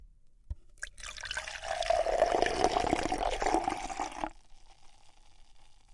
Party Sounds " LÍQUIDO mixdown
描述：som deumlíquidopoSendo despejado em um copo